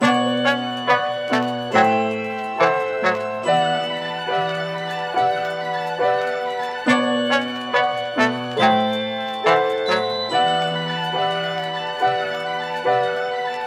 MELODY LOOPS
(140 BPM – Bm)